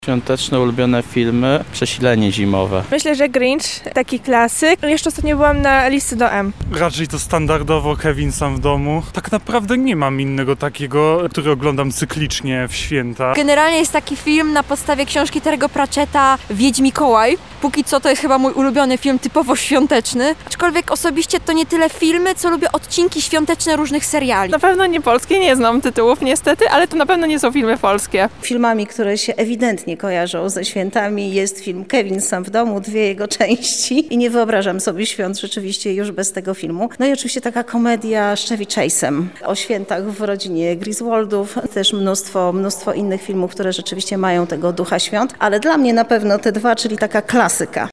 Filmy świateczne, sonda